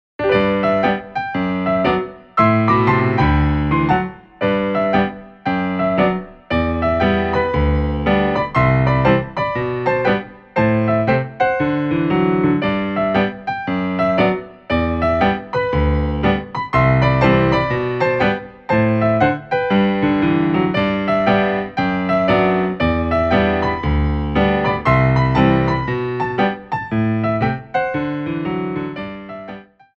33 Inspirational Ballet Class Tracks
Jetés
4/4 (16x8)